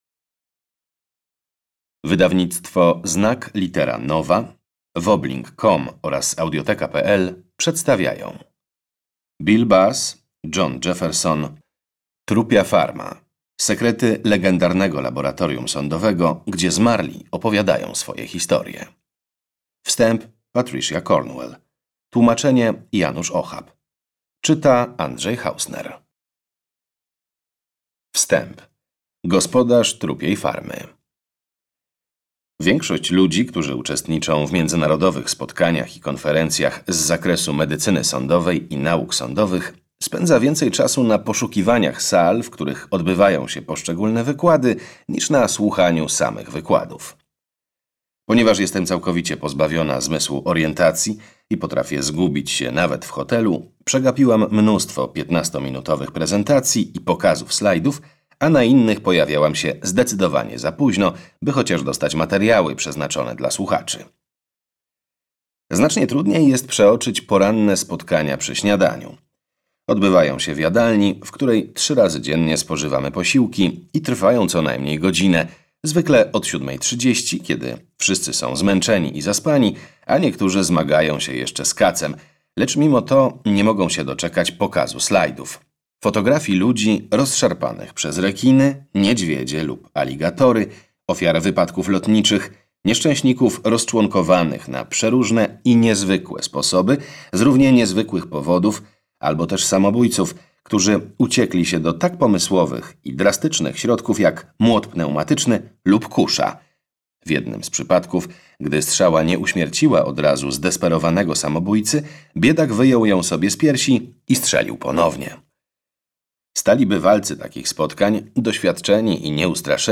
Trupia Farma - Bill Bass - audiobook - Legimi online